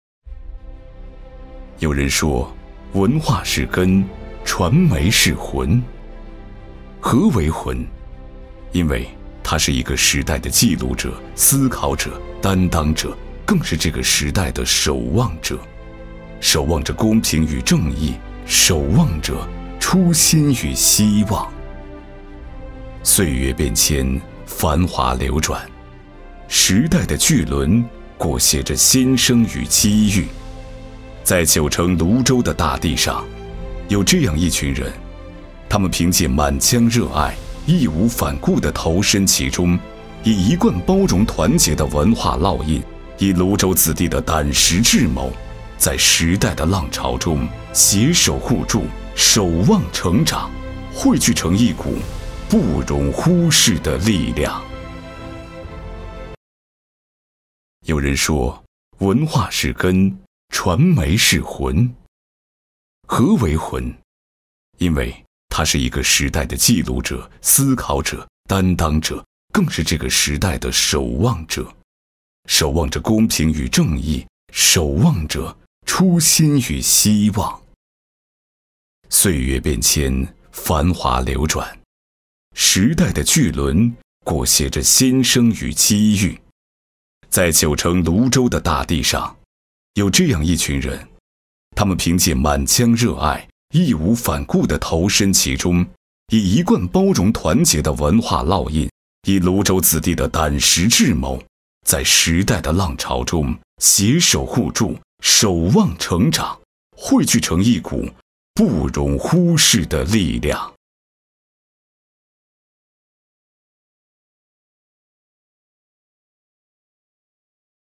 190男-沧桑大气
特点：大气浑厚 稳重磁性 激情力度 成熟厚重
城市专题——泸州【力量 大气 】.mp3